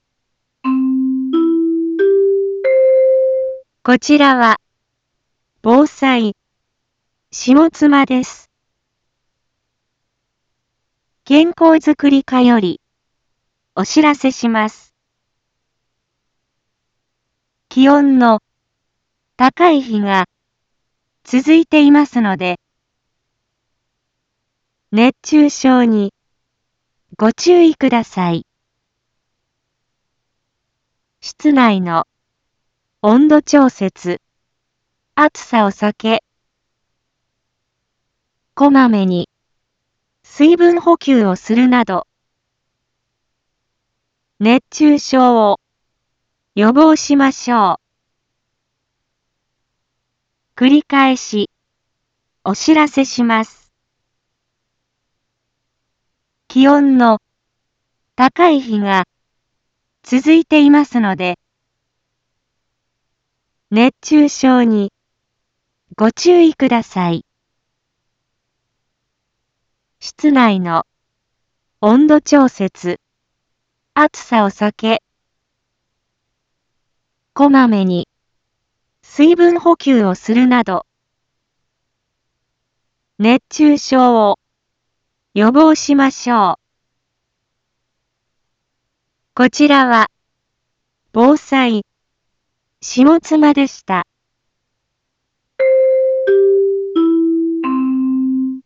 一般放送情報
Back Home 一般放送情報 音声放送 再生 一般放送情報 登録日時：2023-07-06 11:01:42 タイトル：熱中症注意のお知らせ インフォメーション：こちらは、防災、下妻です。